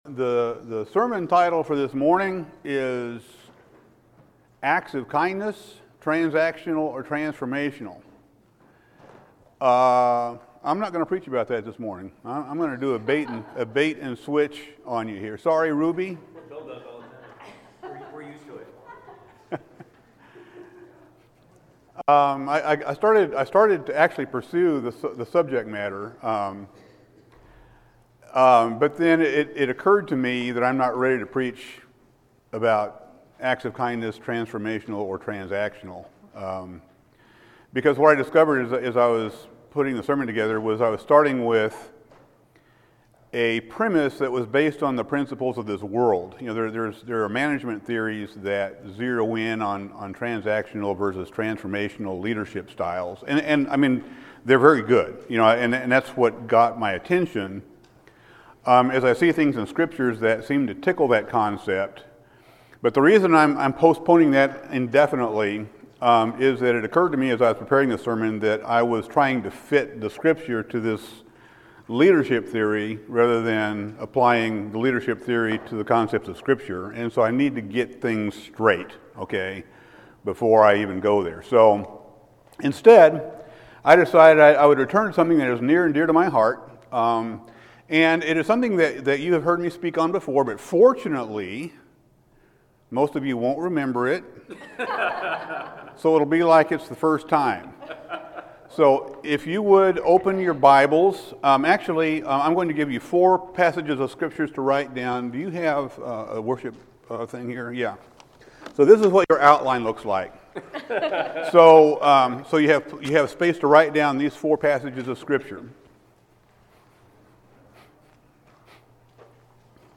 Sermons | Wheeler Road Church of Christ